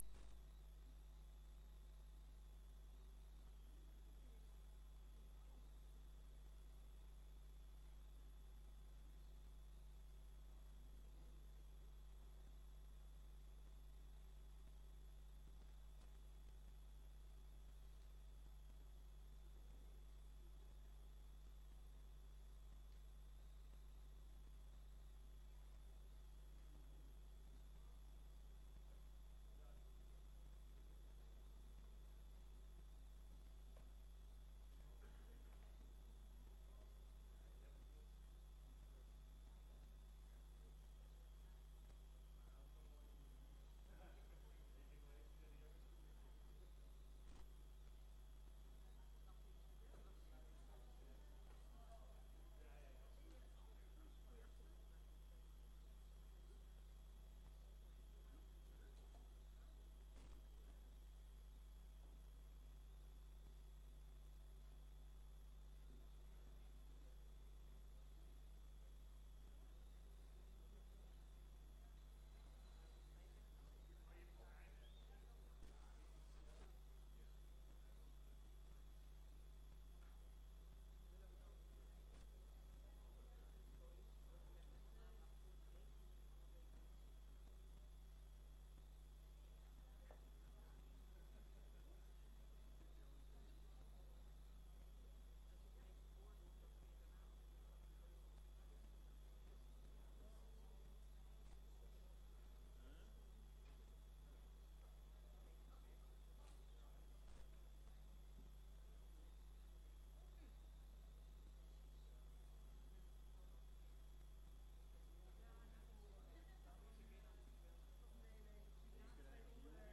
Informatieve raadsvergadering 20 maart 2025 20:00:00, Gemeente Diemen
Locatie: Raadzaal